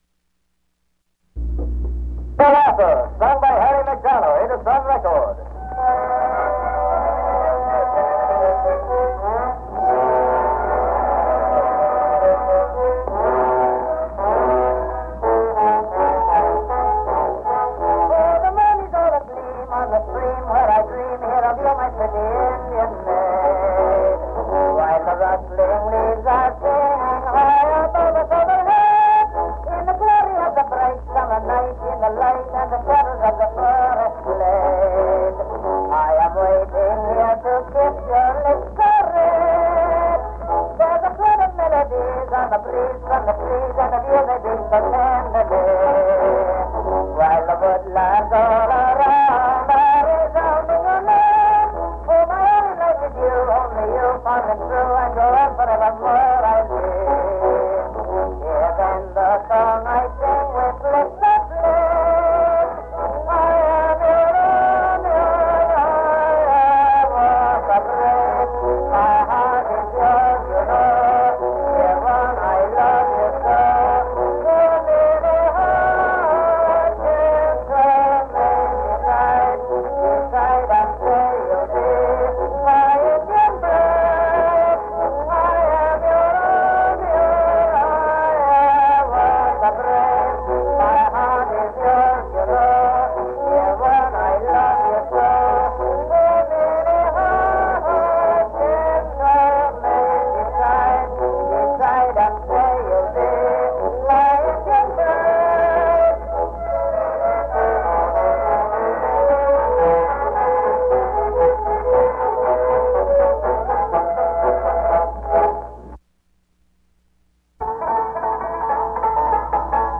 Music (performing arts genre)